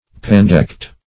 Pandect \Pan"dect\, n. [L. pandecta, pandectes, Gr.